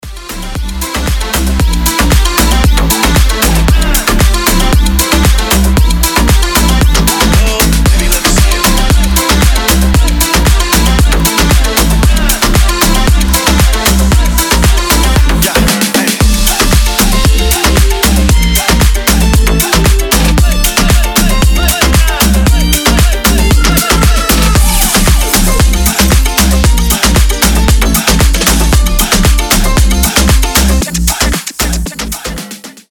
• Качество: 320, Stereo
зажигательные
Club House
Зажигательная музыка, ноги так и пускаются в пляс!